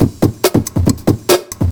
ELECTRO 10-R.wav